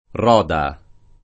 Roda [ r 0 da ]